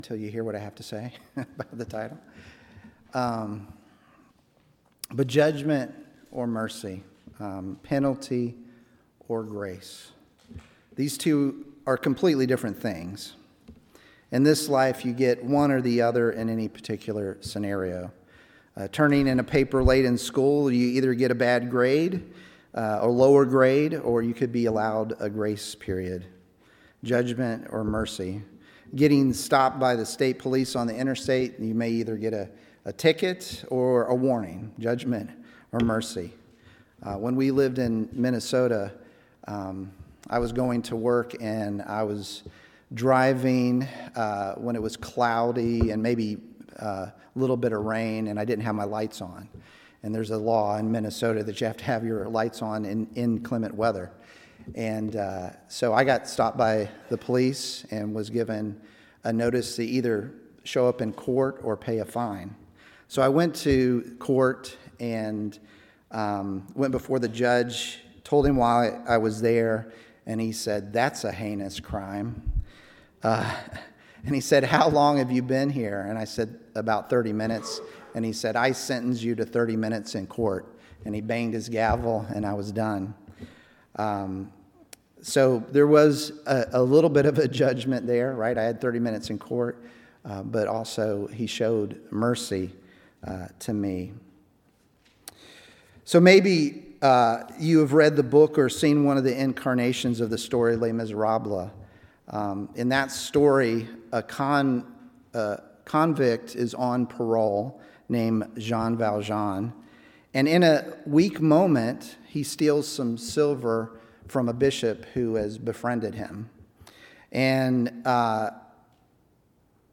John 7:53-8:11 Service Type: Sunday AM Topics: forgiveness , judgment , Salvation « What Is Your Treasure?